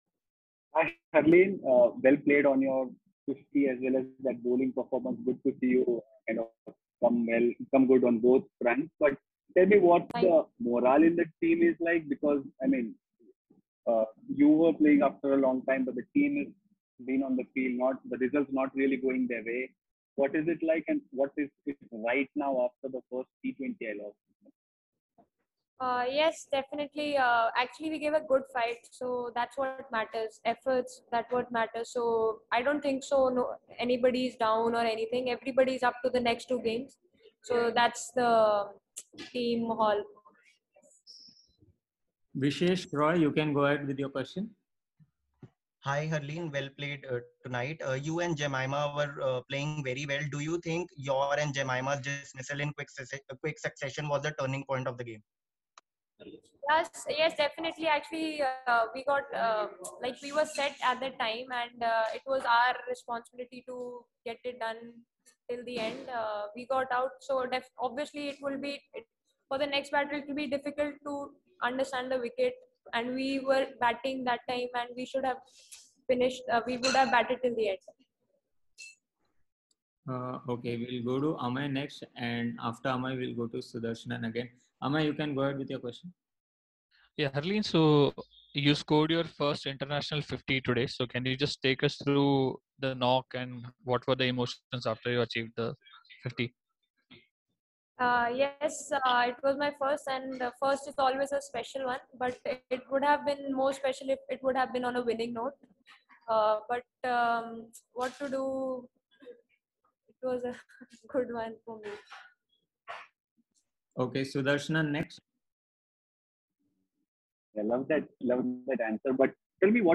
Harleen Deol addressed a virtual press conference after the 1st T20I against South Africa
Harleen Deol, Member, Indian Women’s T20I Team, addressed a virtual press conference after the 1st T20I against South Africa at Lucknow.